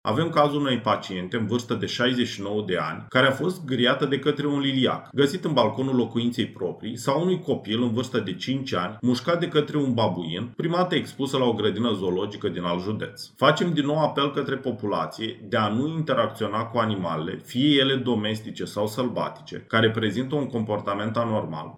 28aug-19-Medic-despre-animale-deosebite.mp3